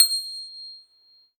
53s-pno25-C6.aif